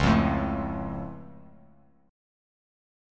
Db+ Chord
Listen to Db+ strummed